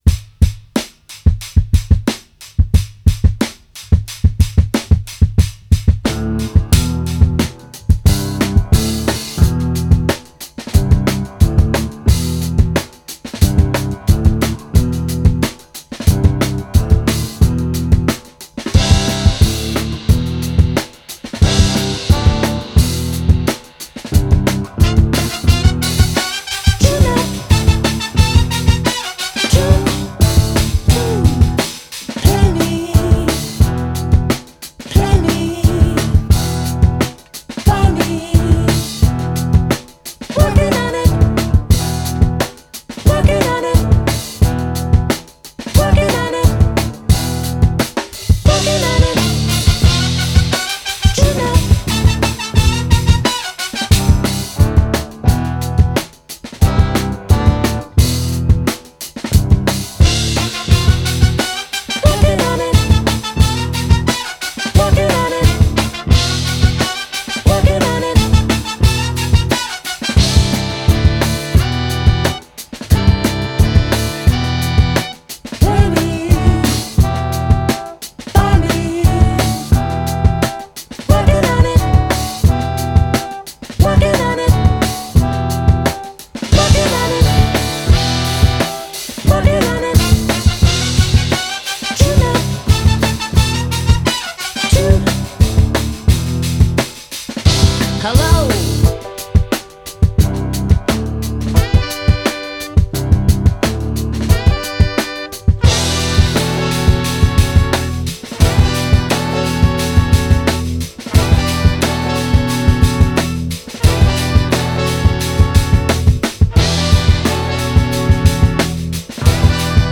A fantastic album for the headphones, to nod your head to.